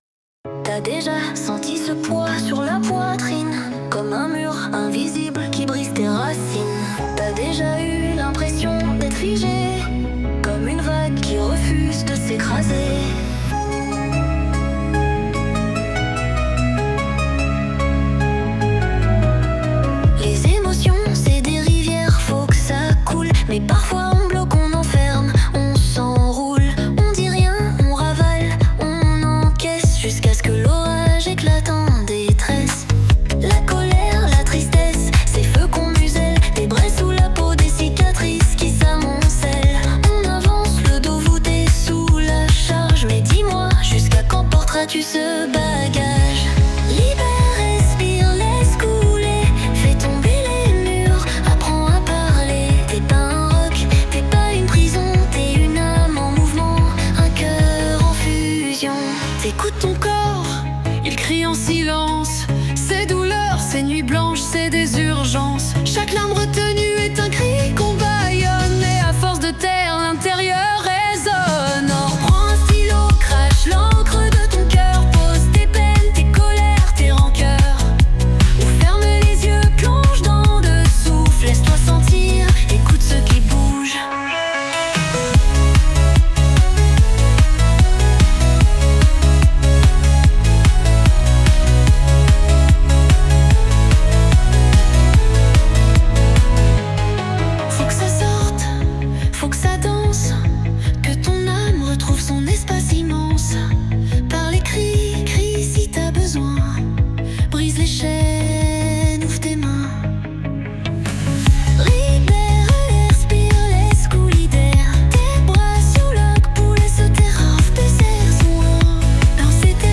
Préparez-vous à plonger dans cette aventure émotionnelle et à découvrir comment alléger votre cœur et votre esprit, en commençant par écouter cette chanson adaptée à la situation :
Libere-tes-emotions-ethereal.mp3